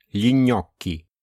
Natomiast rodzajniki lo i l' w liczbie mnogiej zamieniamy na gli (czytamy /li/):